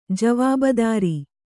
♪ javābadāri